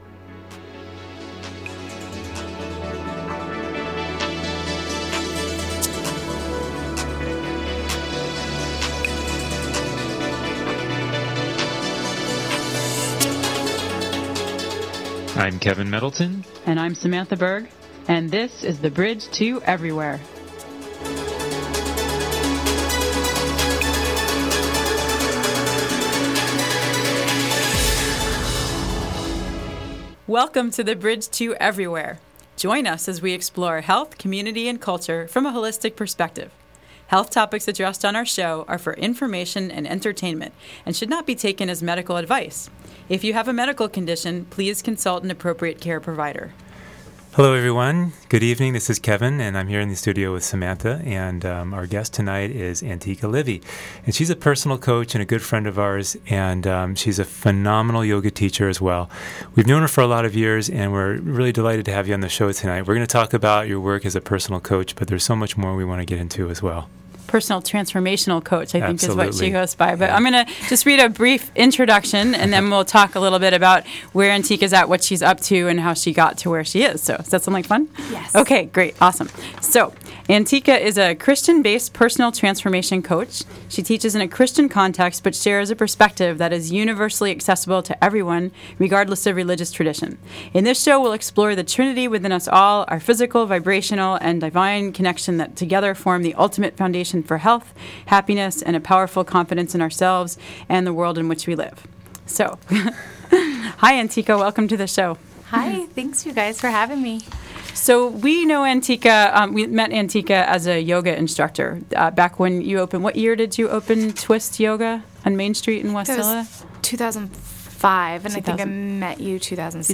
In this show we will explore the Trinity within us all; our physical, vibrational and divine connection that together form the ultimate foundation for health, happiness and a powerful confidence in ourselves and the world in which we live. Broadcast live Monday, October 22 at 7 pm.